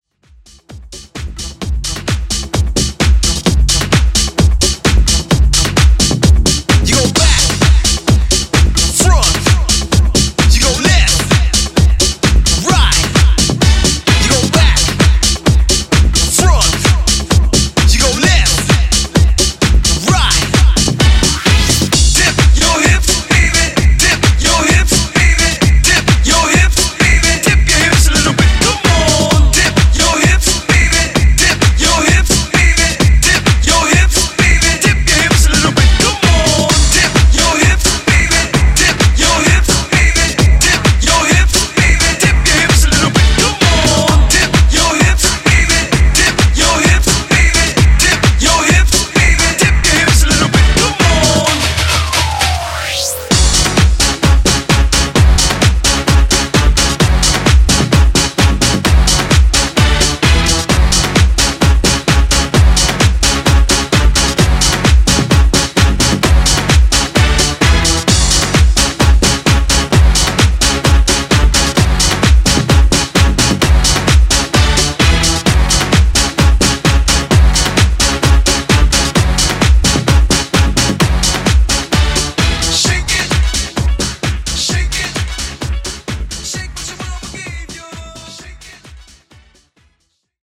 House Rmx)Date Added